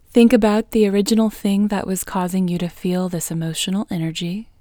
IN – the Second Way – English Female 20